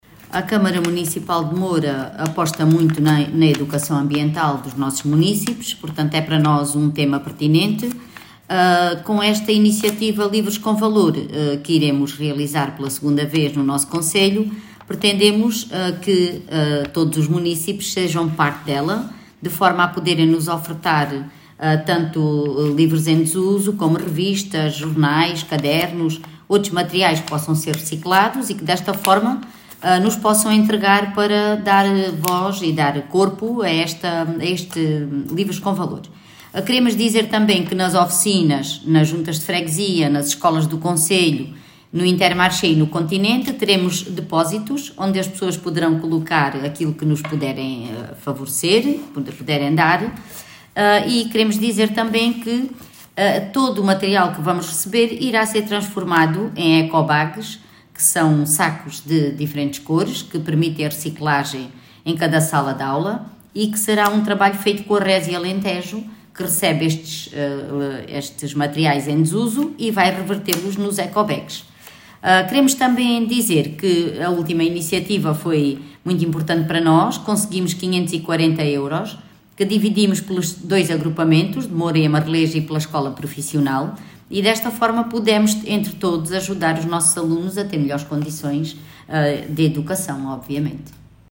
Declaracoes-Vereadora-Lurdes-Balola-Livros-com-Valor.mp3